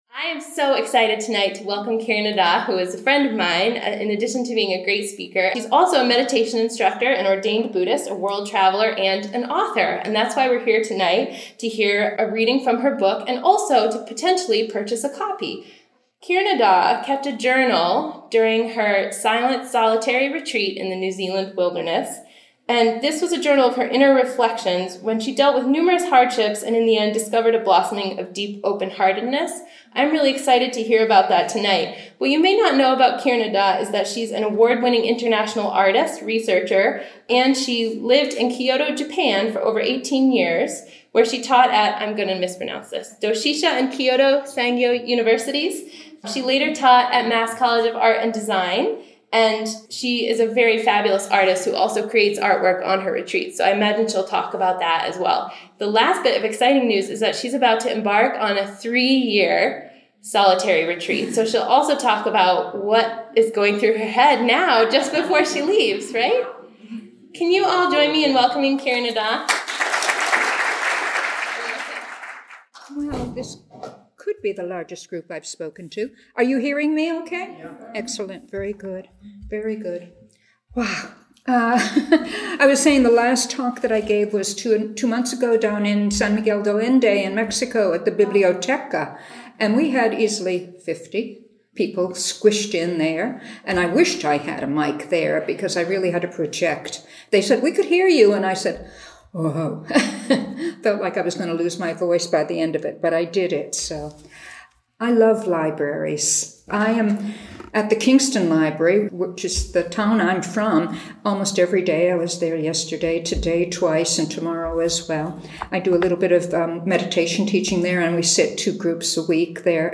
Recorded at the Portsmouth Public Library, New Hampshire, USA.